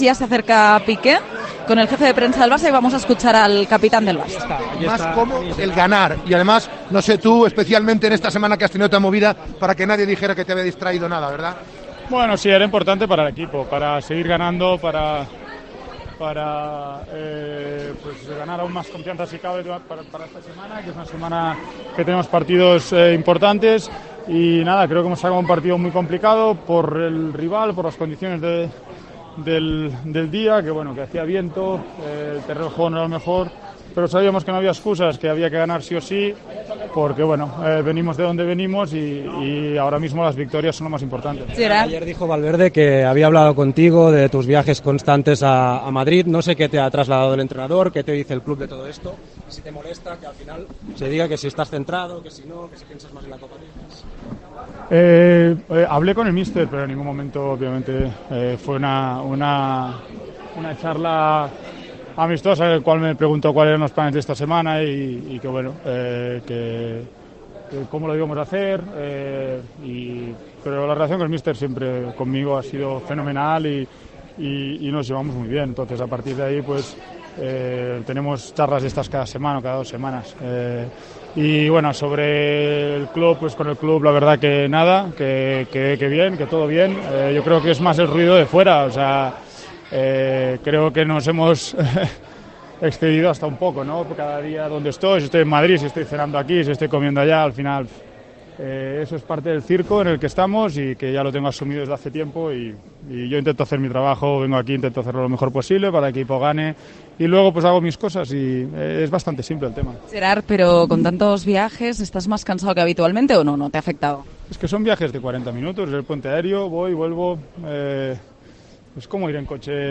El jugador del Barcelona, Gerard Piqué, atendió a los medios de comunicación tras la victoria (1-2) en Butarque ante el Leganés.